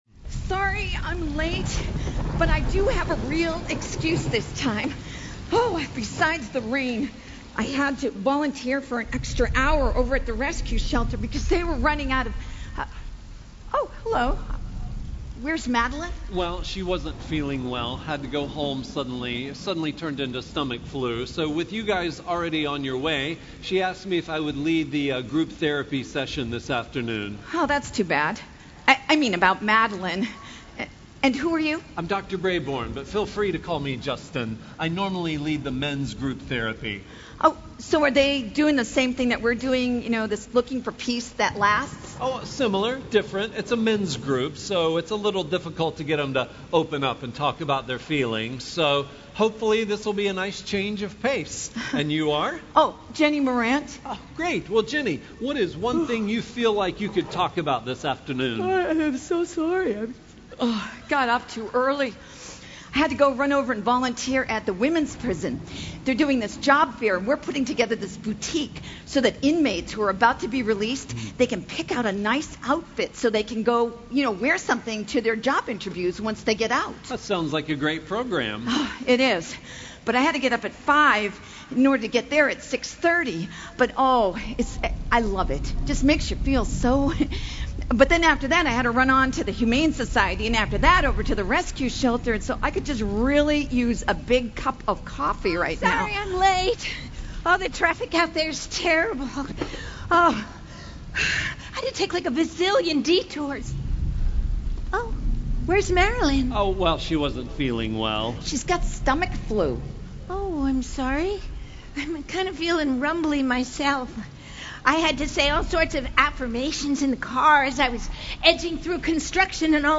Session 5 Drama: Seek Peace and Pursue It | True Woman '18 | Events | Revive Our Hearts
Session 5 Drama: Seek Peace and Pursue It